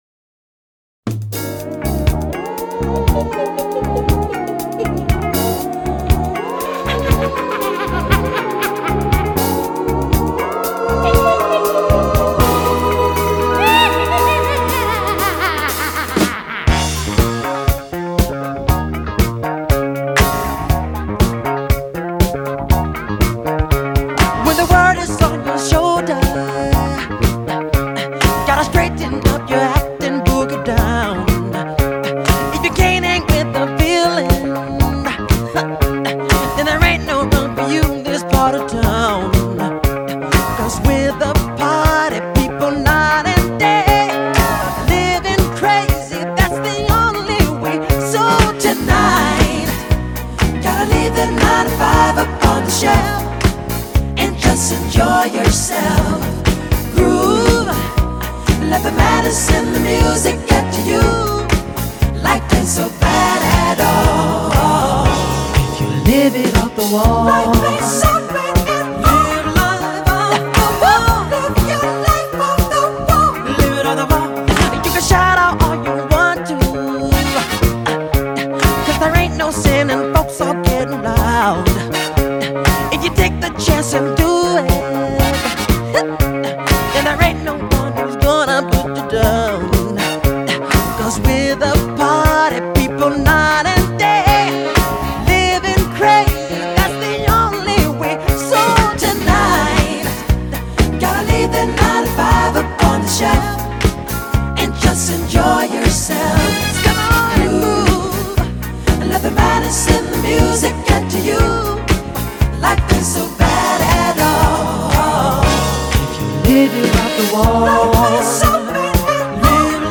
Still chef’s kiss—soaring, infectious, perfectly placed.
Timeless groove, eternal vibe.